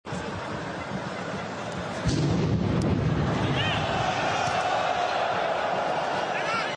Así ha sonado el atentado suicida de París